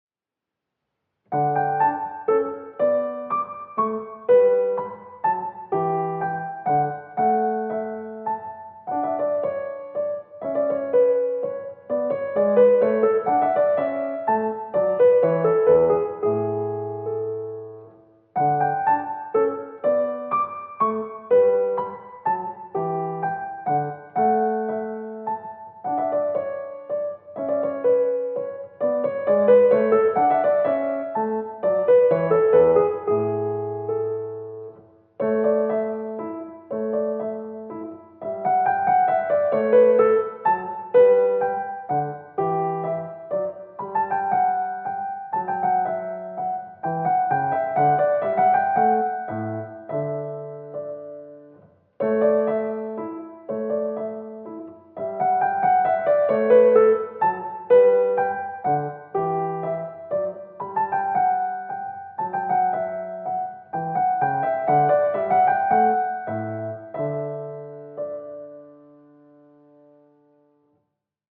mp3Seixas, Carlos de, Sonata No. 21 in D major, mvt.
Minuet